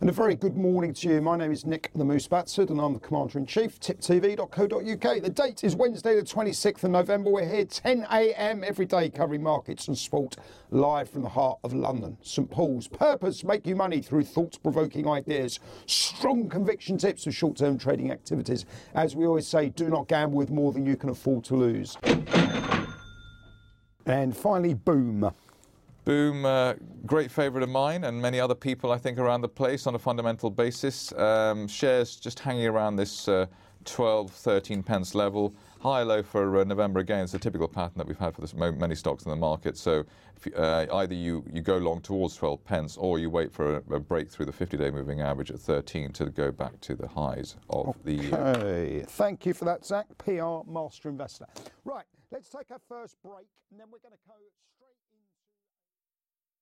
Live Market Round-Up